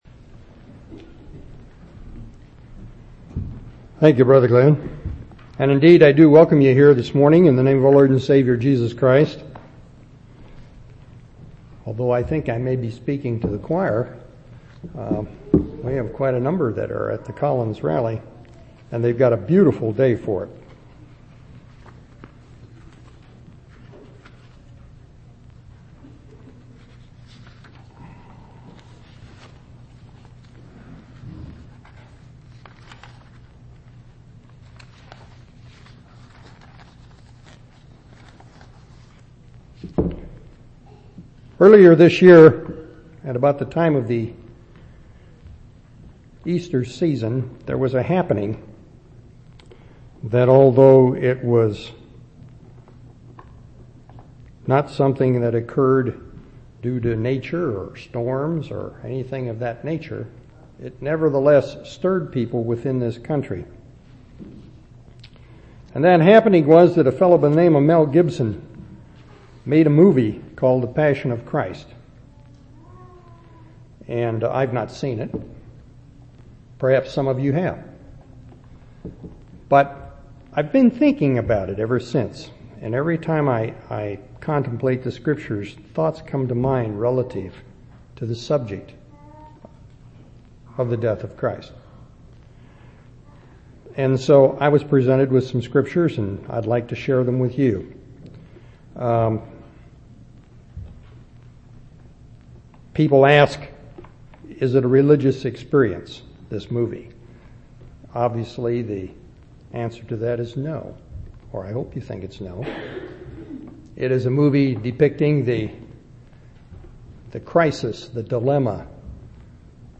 10/17/2004 Location: Temple Lot Local Event